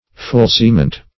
Search Result for " fulciment" : The Collaborative International Dictionary of English v.0.48: Fulciment \Ful"ci*ment\ (f[u^]l"s[i^]*ment), n. [L. fulcimentum, fr. fulcire to prop.] A prop; a fulcrum.